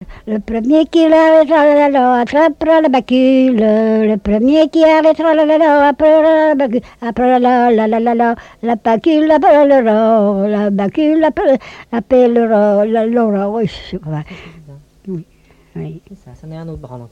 branle
Couplets à danser
collecte du répertoire
Pièce musicale inédite